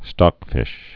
(stŏkfĭsh)